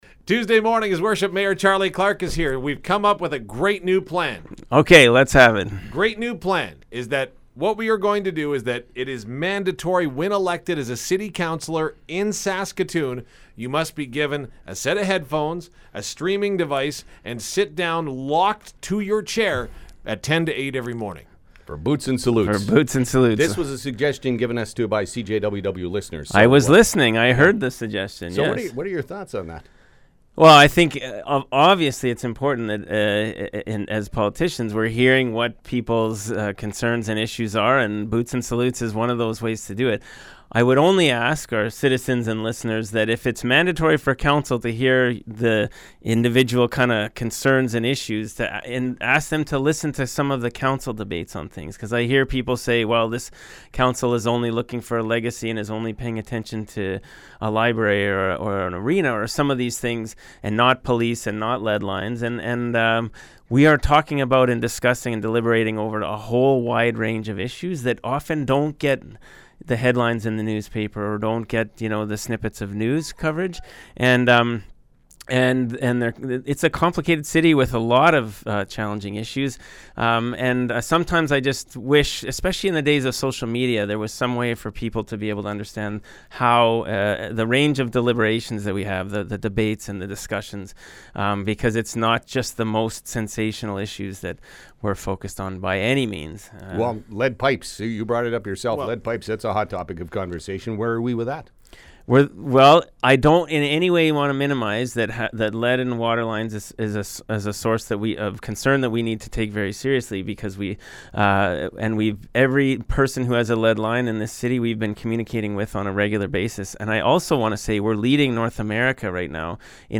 This morning Mayor Charlie Clark made his regular visit to the studio and addressed a CJWW listeners comment that Boots & Salutes should be mandatory listening for Councillors:
Mayor-Charlie-Clark-November-5.mp3